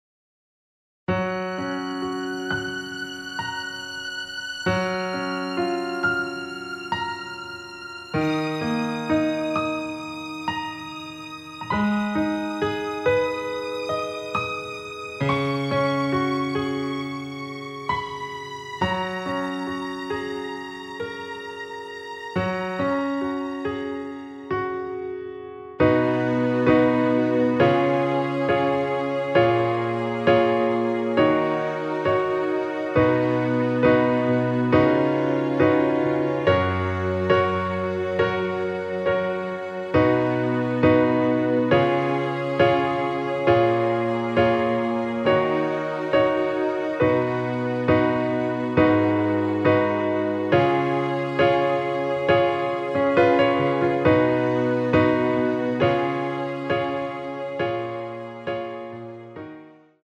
원키에서(+5)올린 MR입니다.
F#
앞부분30초, 뒷부분30초씩 편집해서 올려 드리고 있습니다.
중간에 음이 끈어지고 다시 나오는 이유는